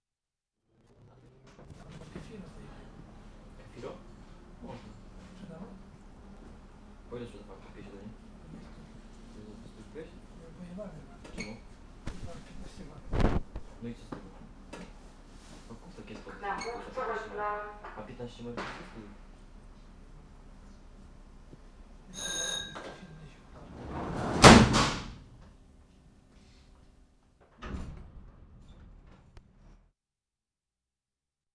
S-Bahn City Train from inside (an older one, ringing bell as a caution signal)